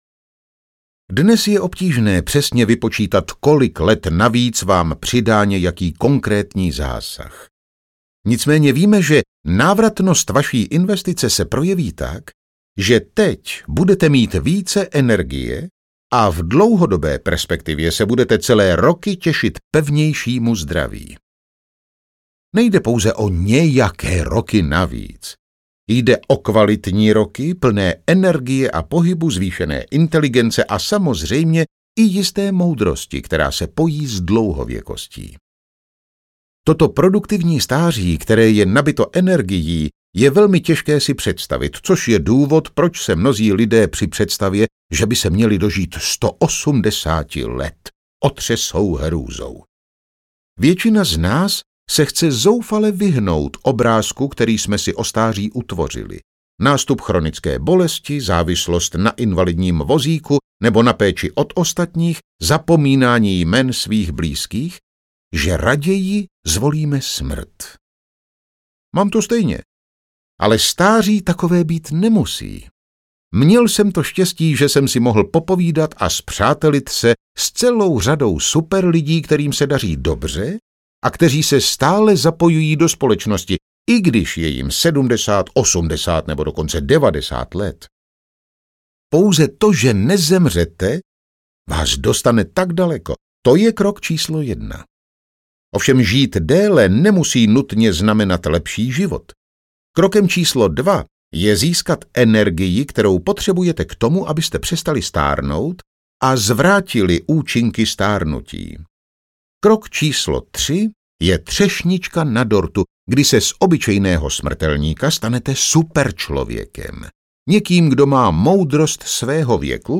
Hacknuté tělo audiokniha
Ukázka z knihy